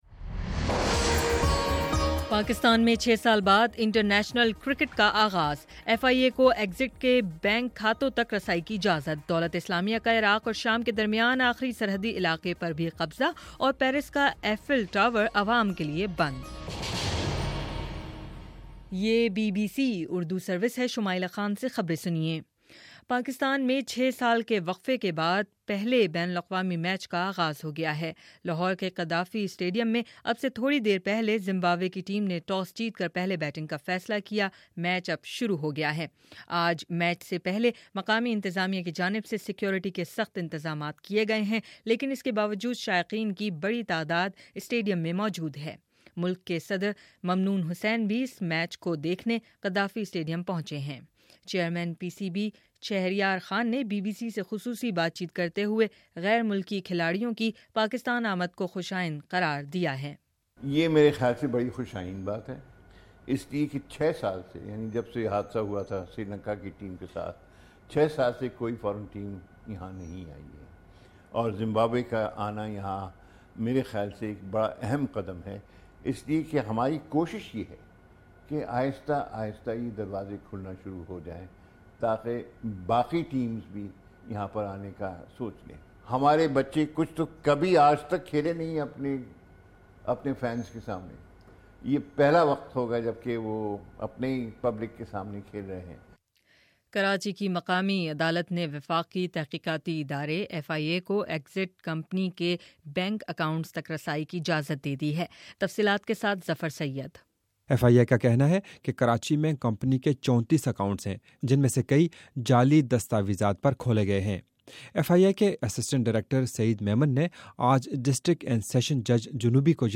مئی 22: شام سات بجے کا نیوز بُلیٹن